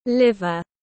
Gan tiếng anh gọi là liver, phiên âm tiếng anh đọc là /ˈlɪvə/
Liver /ˈlɪvə/